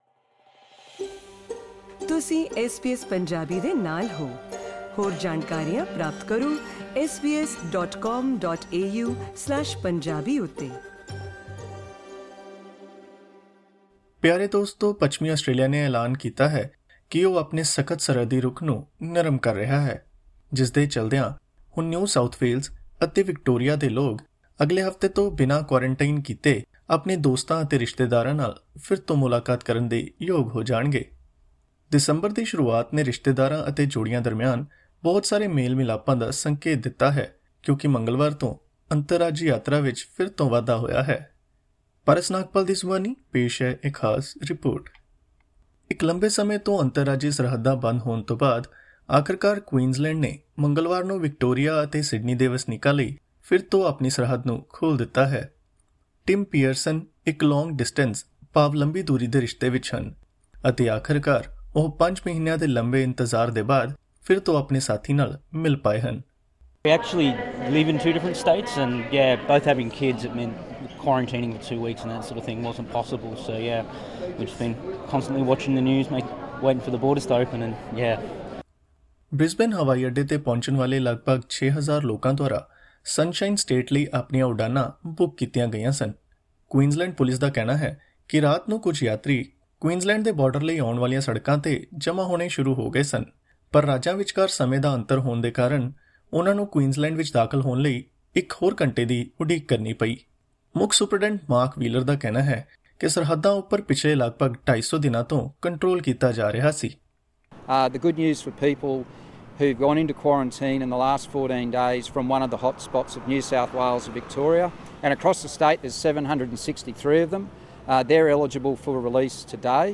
Click on the audio icon to listen to the full audio report.